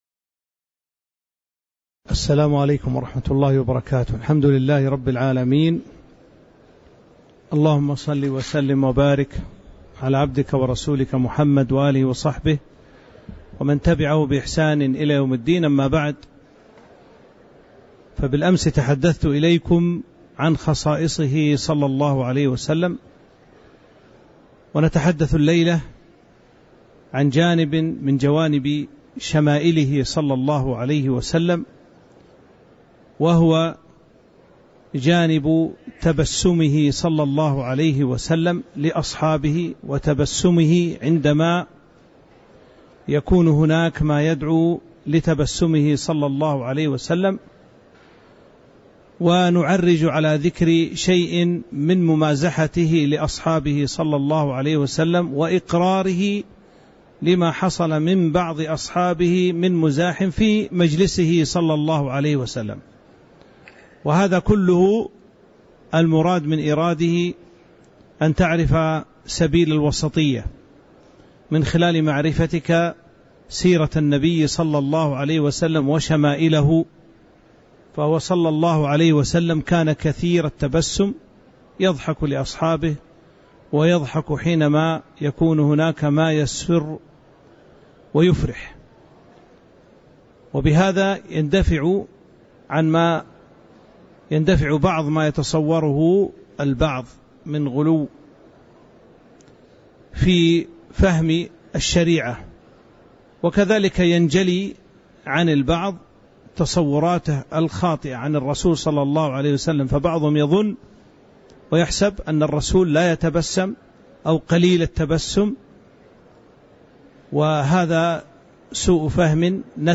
تاريخ النشر ٢٠ ذو الحجة ١٤٤٥ هـ المكان: المسجد النبوي الشيخ